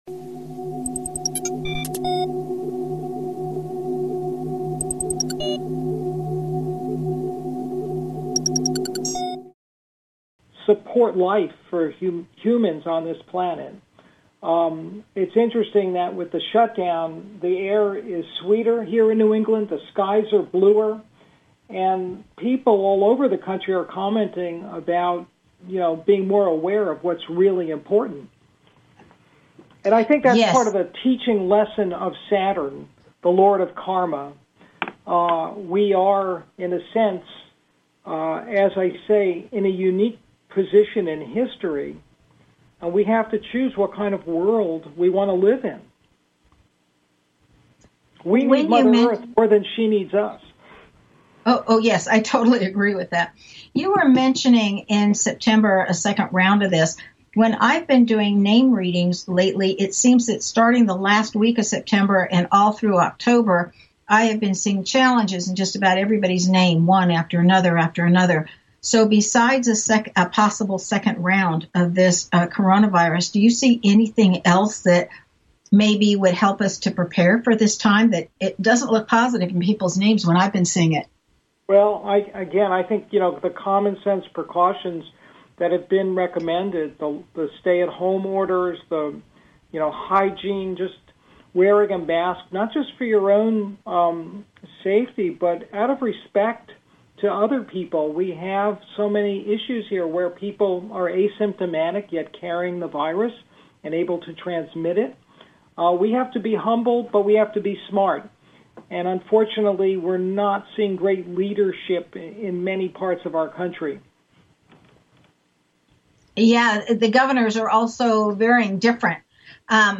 Guest Occupation Astrologer